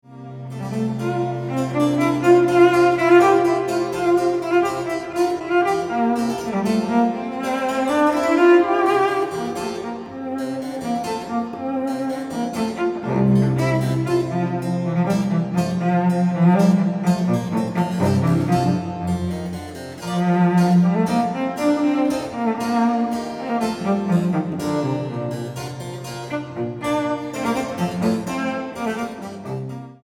violoncelo